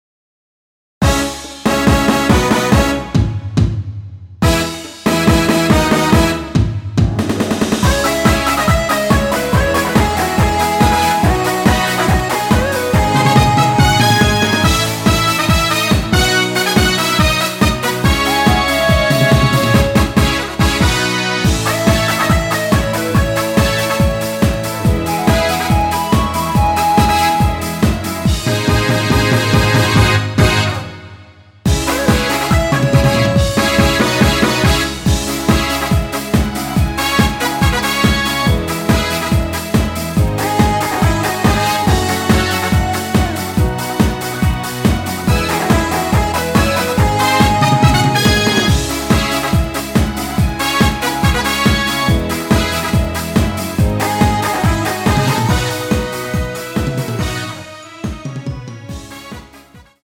C#m
◈ 곡명 옆 (-1)은 반음 내림, (+1)은 반음 올림 입니다.
앞부분30초, 뒷부분30초씩 편집해서 올려 드리고 있습니다.
중간에 음이 끈어지고 다시 나오는 이유는